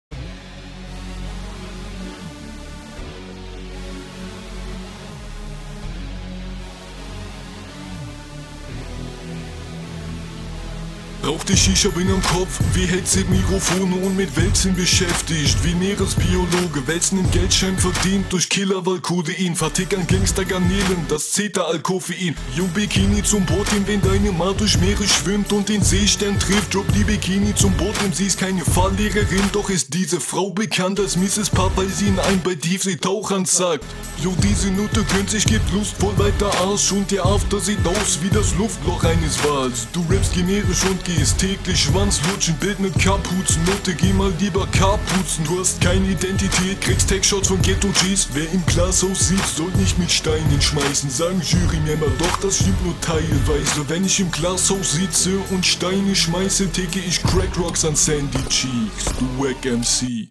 Und wieder ein SpongeBOZZ-Beat...